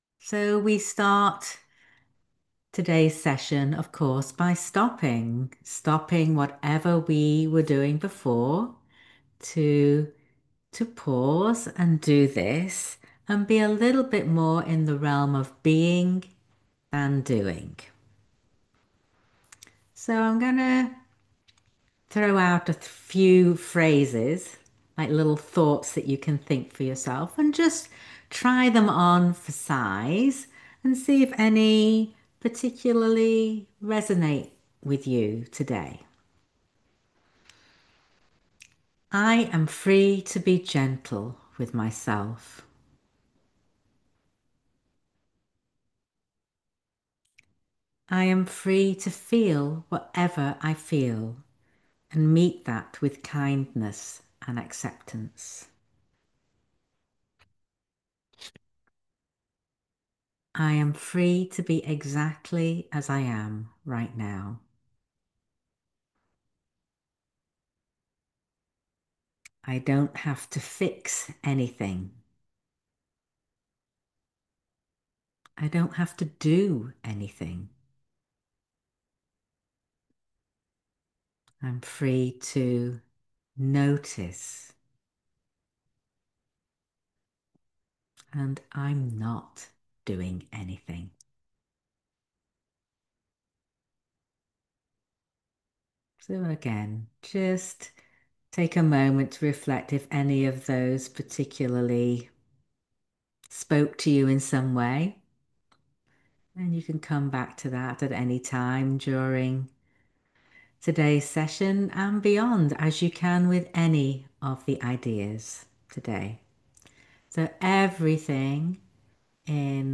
talk given during a live session on September 30, 2025. Listen to it during Constructive Rest to guide your thinking and awareness.